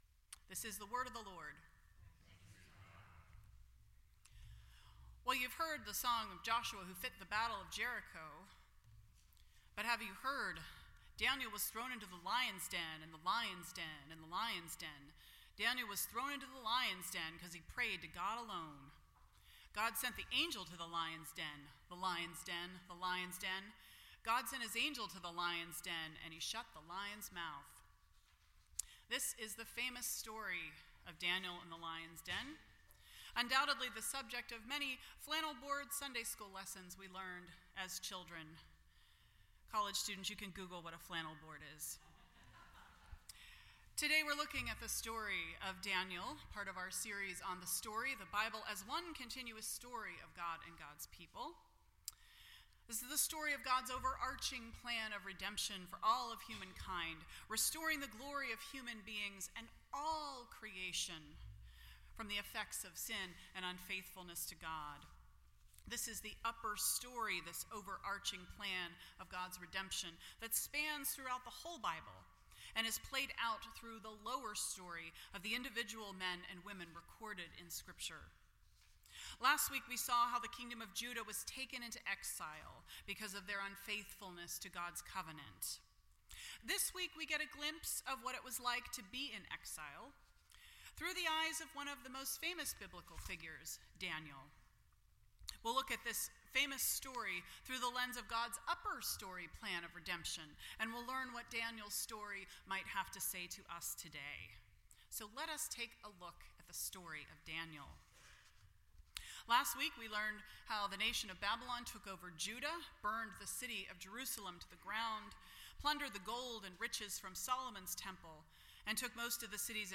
The Story Service Type: Sunday Morning %todo_render% Share This Story